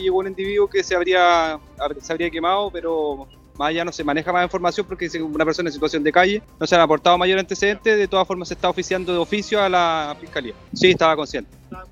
carabinero.mp3